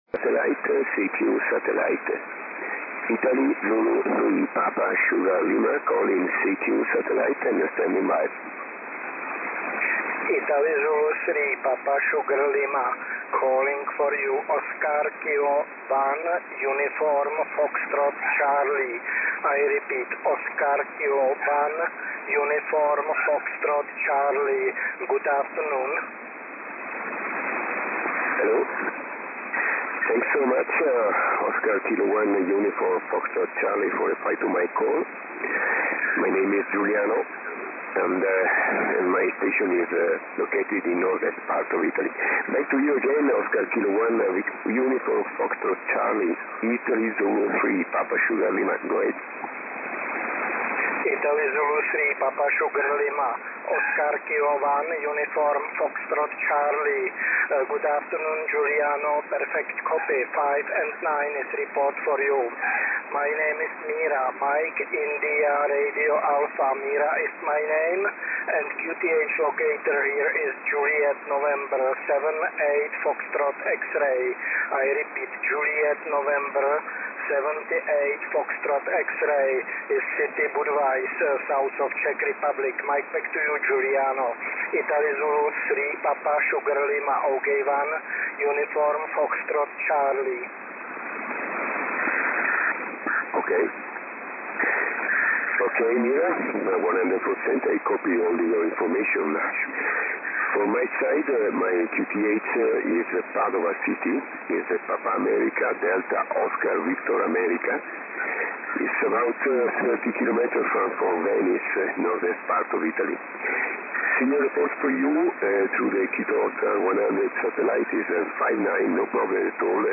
Na závěr malá ukázka SSB QSO s výkonem 5W a s použitím malé planární antény o rozměrech 34x34 cm.